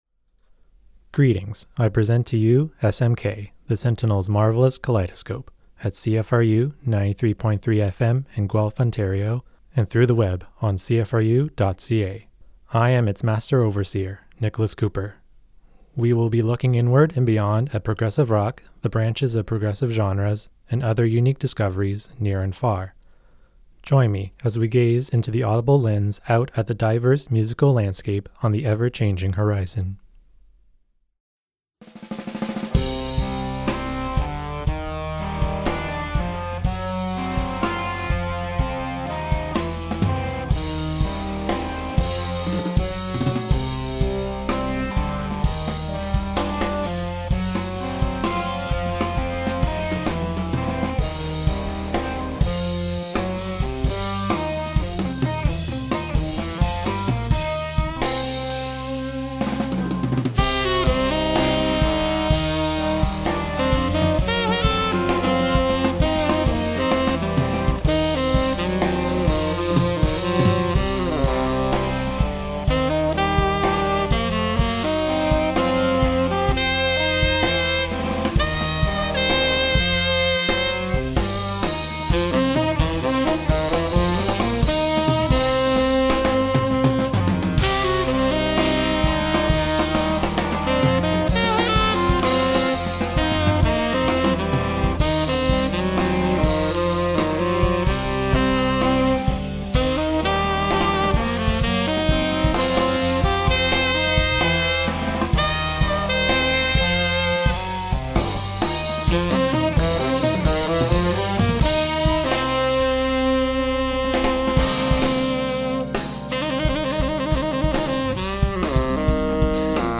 Looking inward and beyond at progressive, fusion and alternative genres, near and far...